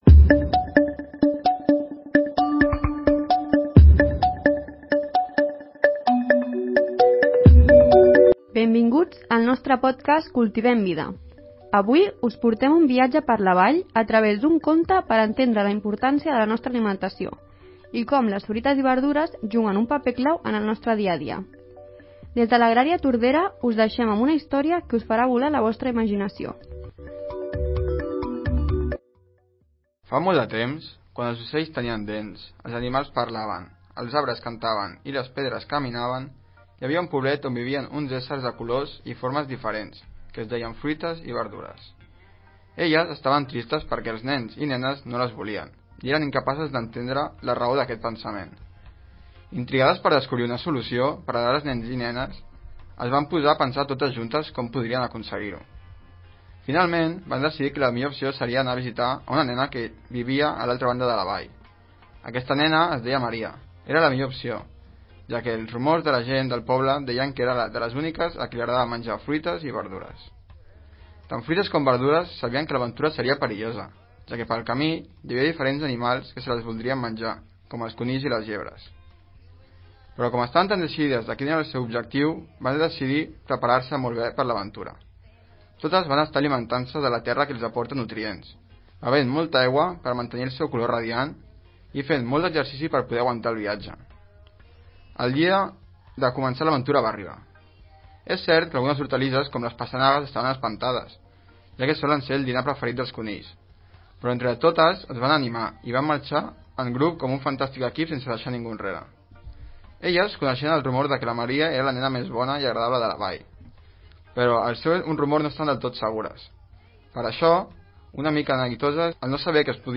Conte infantil.